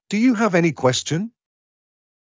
ﾄﾞｩ ﾕｰ ﾊﾌﾞ ｴﾆｰ ｸｴｯｼｮﾝ